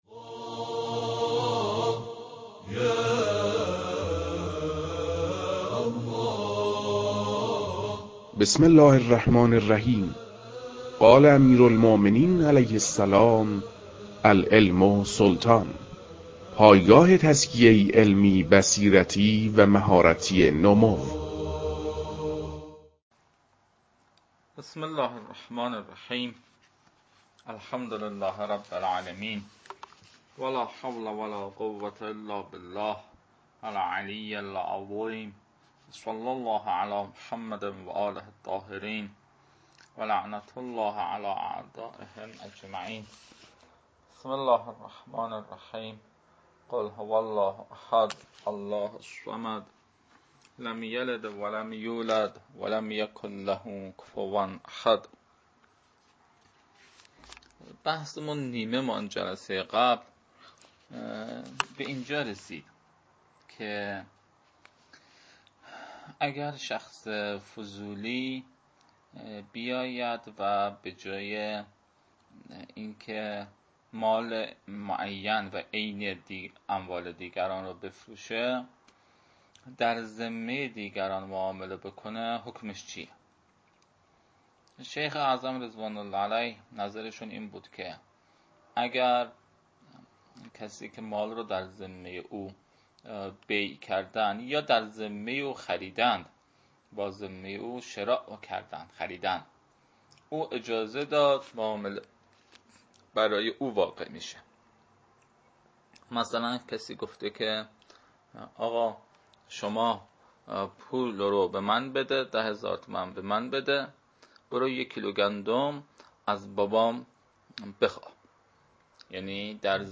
تدریس